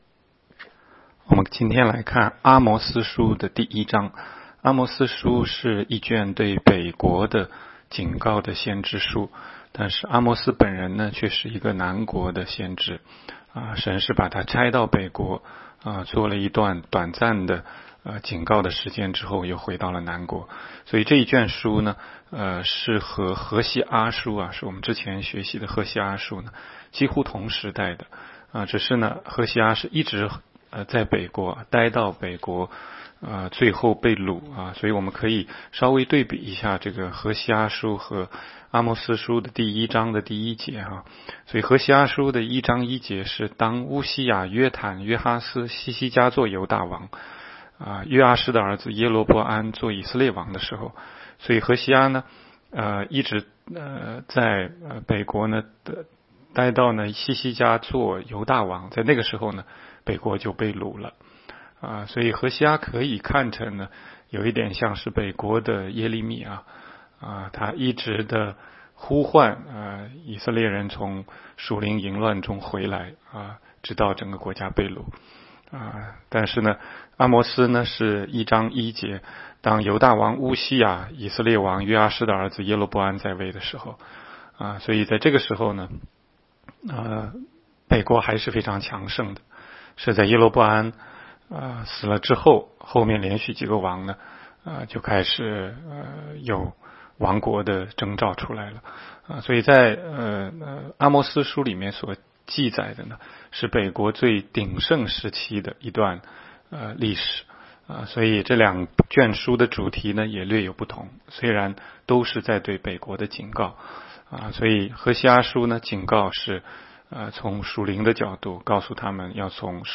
16街讲道录音 - 每日读经 -《阿摩司书》1章